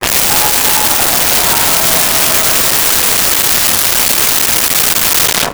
Large Crowd Applause 03
Large Crowd Applause 03.wav